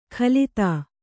♪ khalitā